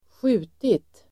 Uttal: [²sj'u:tit]